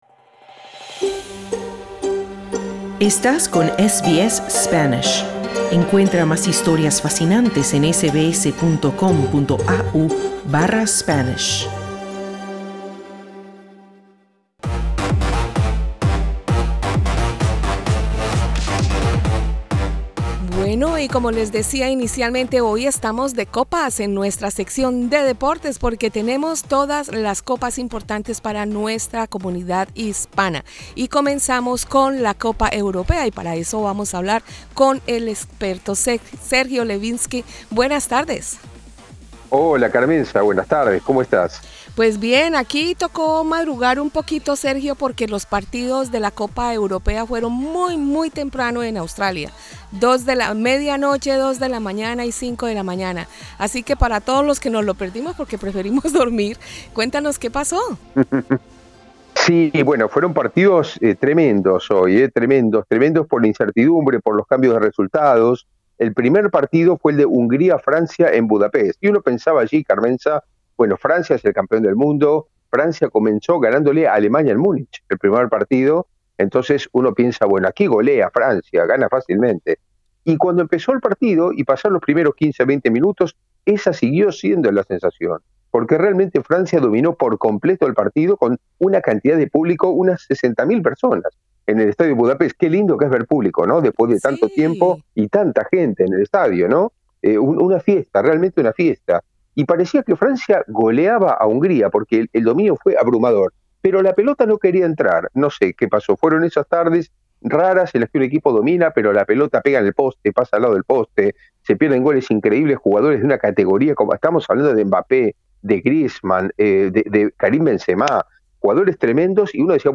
Fiesta de goles en jornada del sabado en la Eurocopa, mientras Colombia, Perú, Venezulea y Ecuador se preparan para la siguiente fecha en la Copa América. Análisis del comentarista deportivo